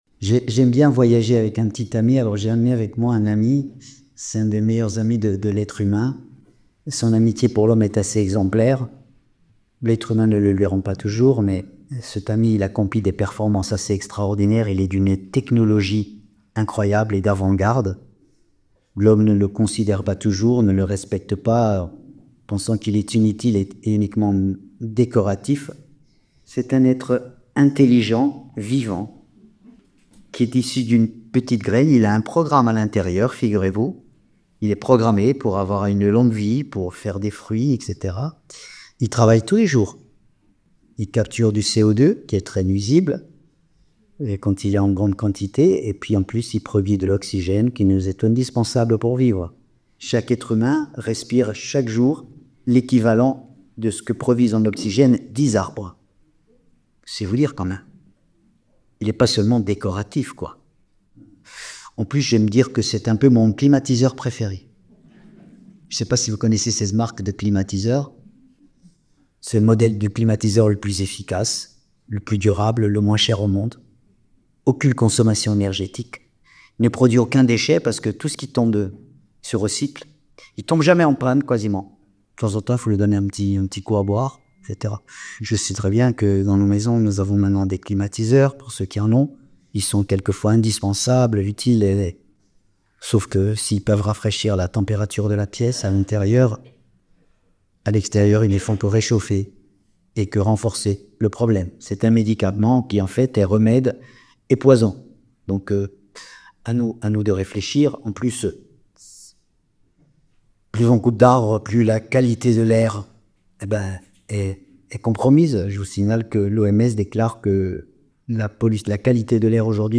Conférence de la semaine du 31 déc.